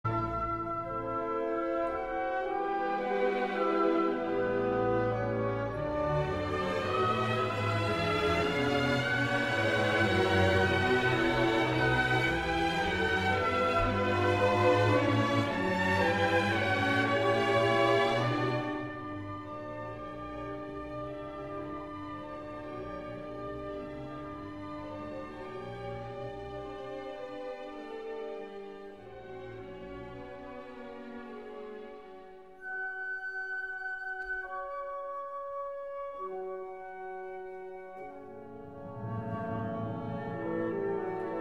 第二主题1:09是木管带头抒情旋律，弦乐以半音关系回应，接着三音下音型反覆地进行，意象薄弱之际，突然浮现木管群的4∘大跳旋律，稍纵即逝，后面仍以半音关系延续。
乐团主题二1:09的抒情旋律和大跳